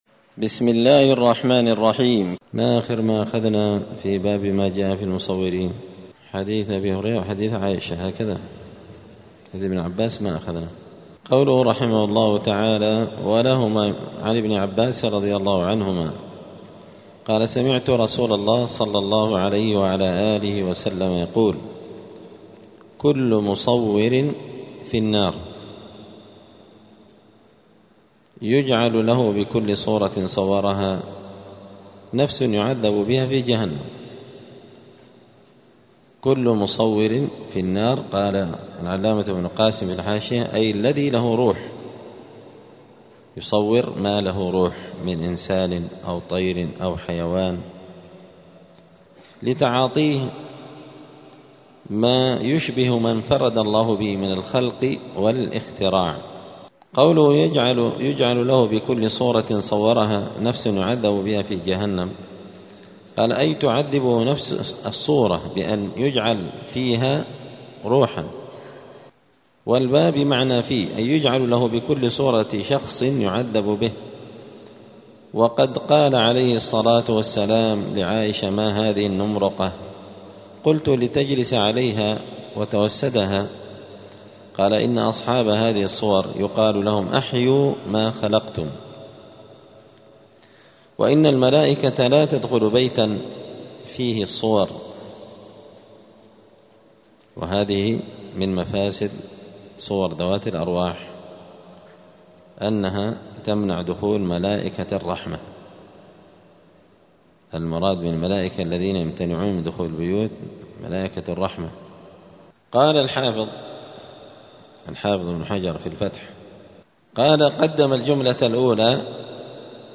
دار الحديث السلفية بمسجد الفرقان قشن المهرة اليمن
*الدرس السادس والأربعون بعد المائة (146) {تابع لباب ما جاء في المصورين}*